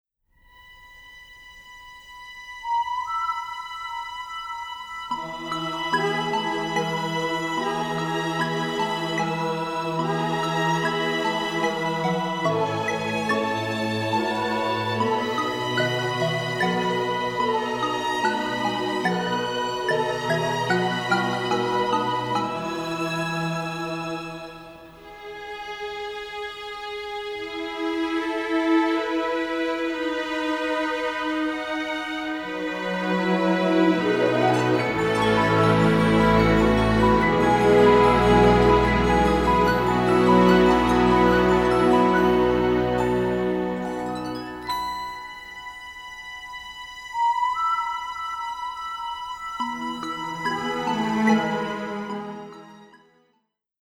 Orchestral Film Version